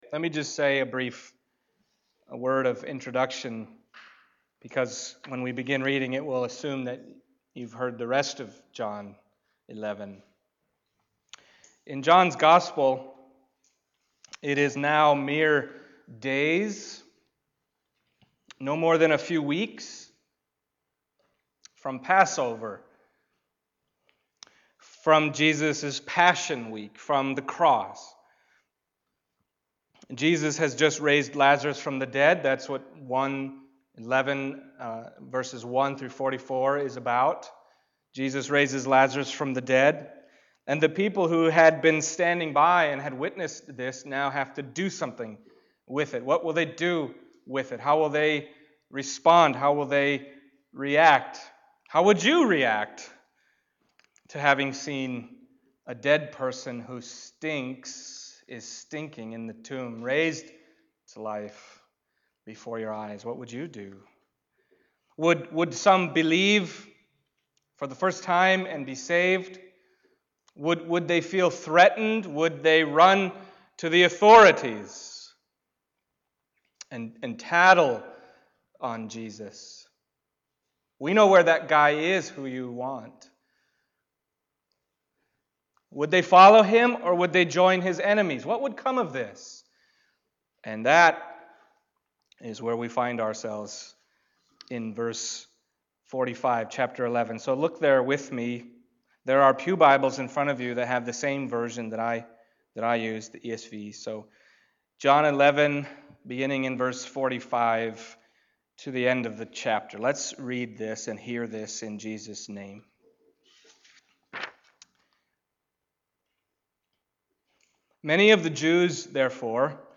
John Passage: John 11:45-57 Service Type: Sunday Morning John 11:45-57 « A Resurrection …